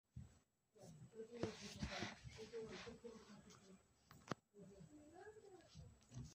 שיעור מ 10 ינואר 2024